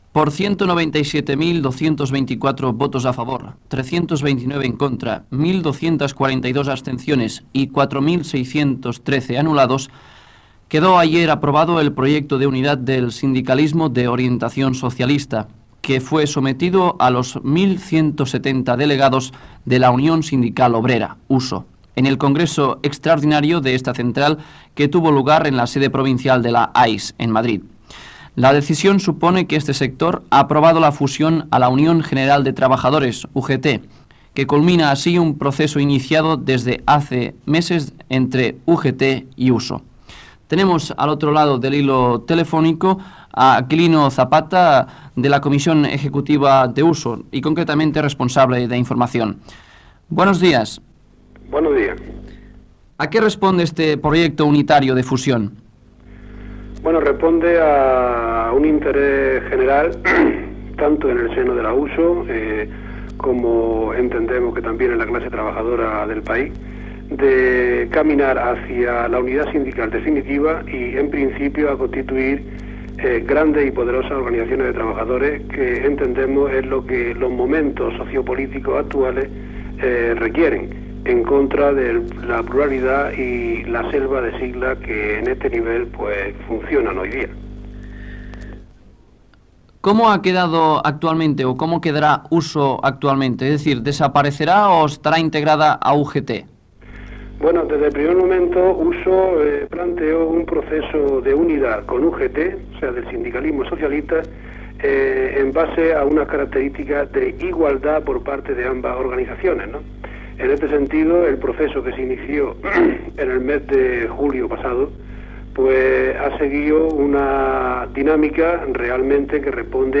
Entrevista
sobre el projecte unitari de fusió entre la Unió Sindical Obrera (USO) i la Unió General de Treballadors (UGT) Gènere radiofònic Informatiu